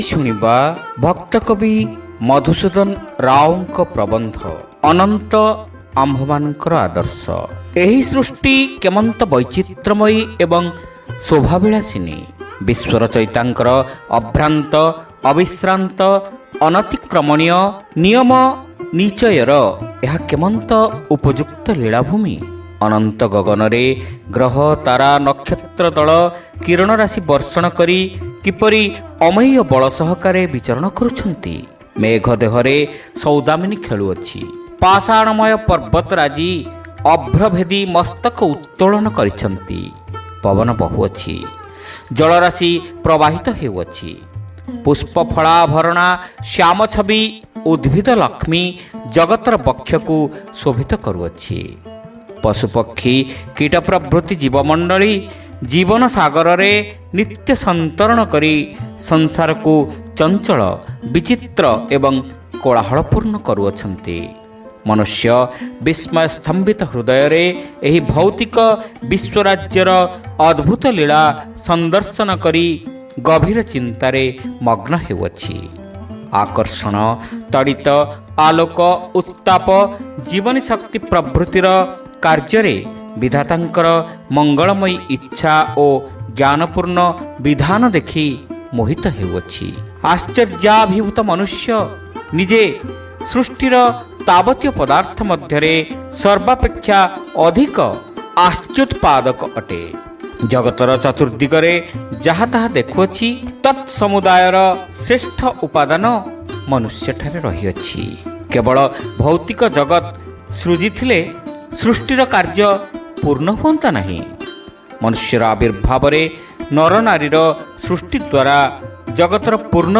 ଶ୍ରାବ୍ୟ ଗଳ୍ପ : ଅନନ୍ତ ଆମ୍ଭମାନଙ୍କ ଆଦର୍ଶ